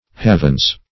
halvans - definition of halvans - synonyms, pronunciation, spelling from Free Dictionary Search Result for " halvans" : The Collaborative International Dictionary of English v.0.48: Halvans \Hal"vans\ (h[a^]l"vanz), n. pl. (Mining) Impure ore; dirty ore.